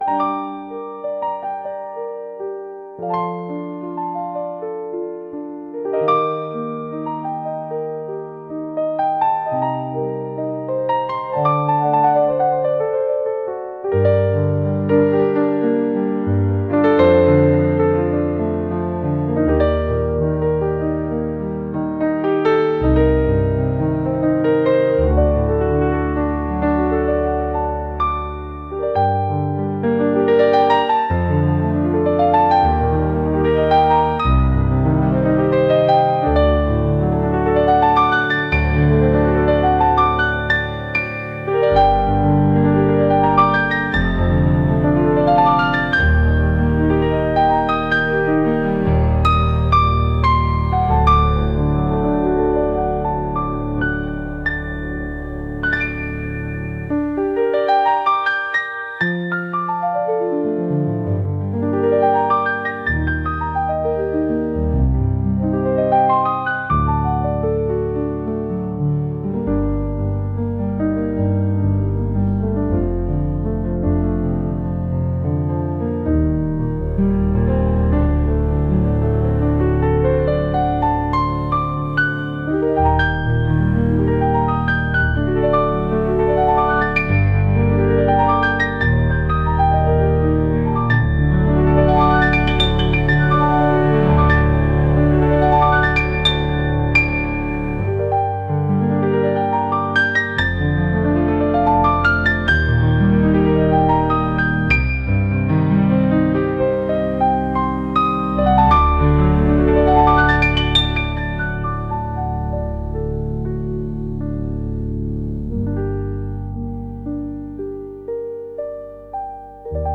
お城の中をさまようような重苦しいピアノ曲です。